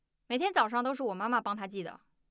0009_000461_Angry.wav